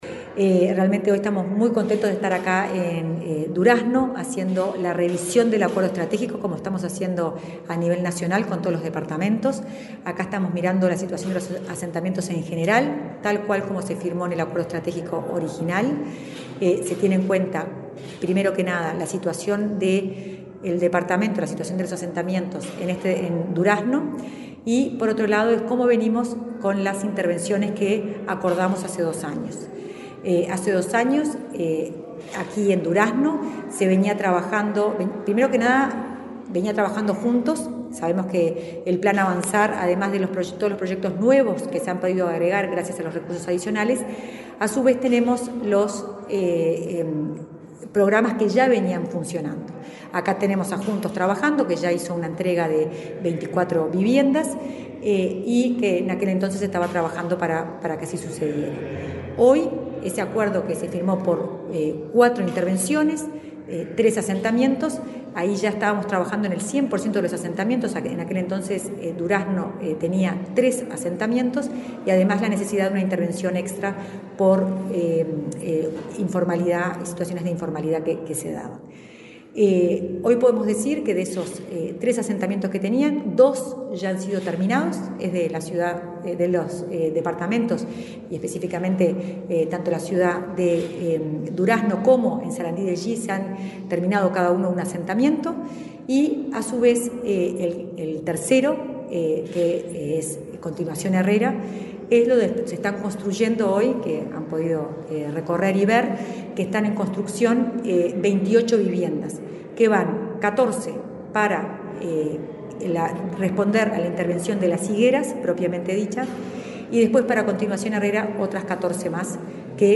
Entrevista a la directora de Integración Social y Urbana del Ministerio de Vivienda, Florencia Arbeleche
La directora de Integración Social y Urbana del Ministerio de Vivienda, Florencia Arbeleche, dialogó con Comunicación Presidencial en Durazno, donde mantuvo una reunión con técnicos de la intendencia local, para la revisión del acuerdo estratégico acerca de trabajos en asentamientos, en el marco del plan Avanzar.